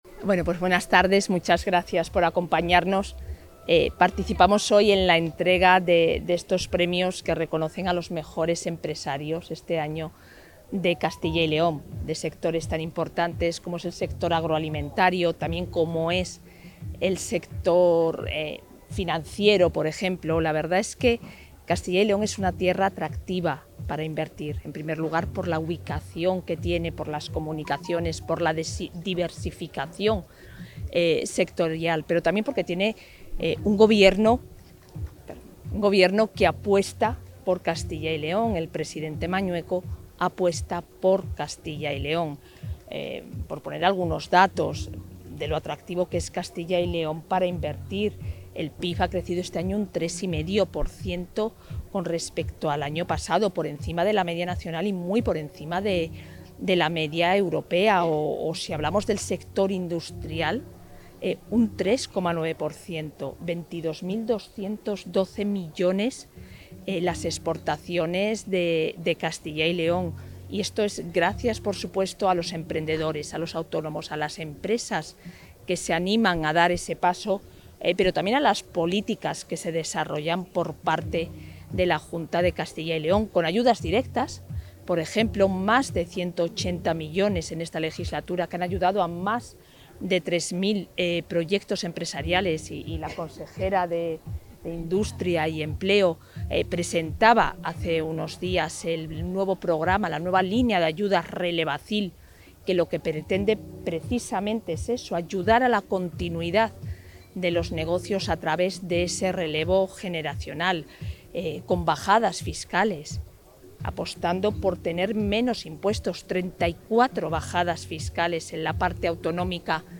La vicepresidenta de la Junta participa en la gala de entrega de la II Edición de los Premios Empresa del Año Banco Sabadell
Intervención de la vicepresidenta.
La vicepresidenta de la Junta de Castilla y León y consejera de Familia e Igualdad de Oportunidades, Isabel Blanco, ha participado esta tarde en la gala de entrega de la II Edición de los Premios Empresa del Año Banco Sabadell organizados por La Opinión de Zamora.